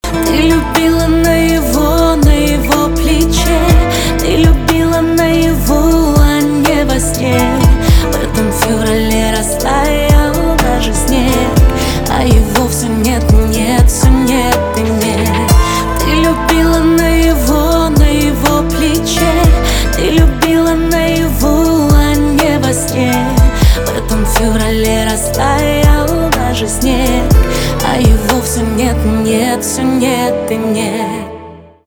поп
грустные , печальные
битовые , пианино